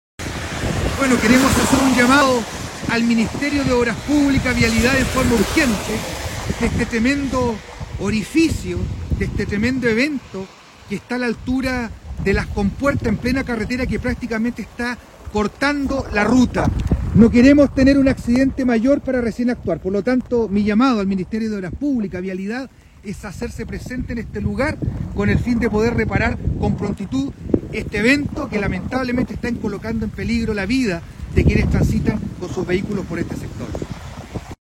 ALCALDE-VERA-LLAMA-A-REPARAR-EVENTO.mp3